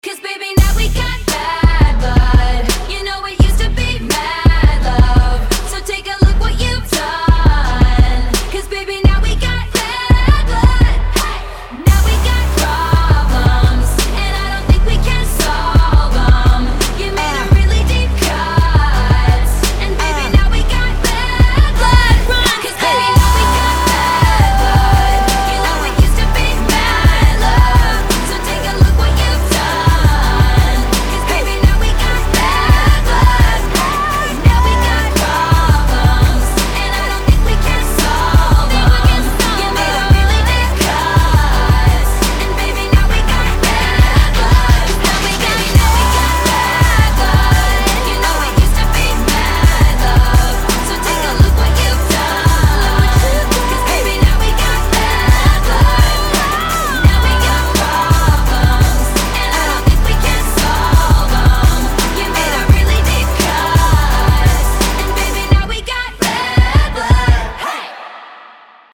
поп
женский вокал
RnB